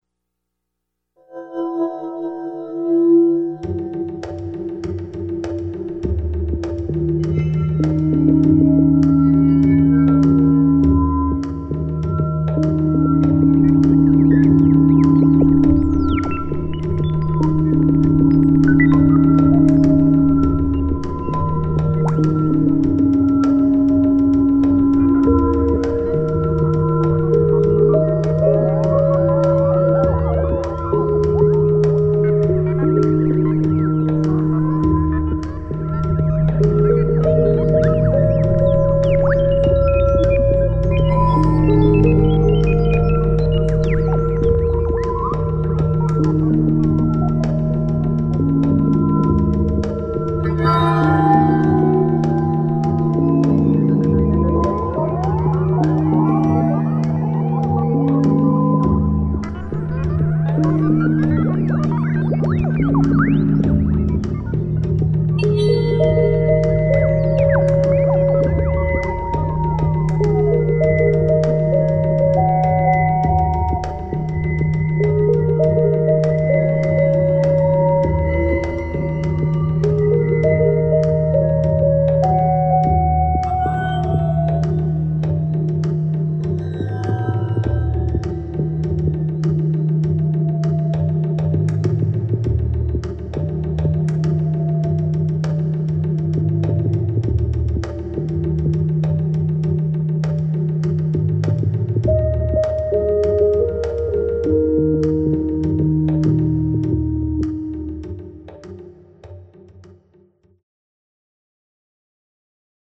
Dark ambient electronic